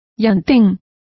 Complete with pronunciation of the translation of plantain.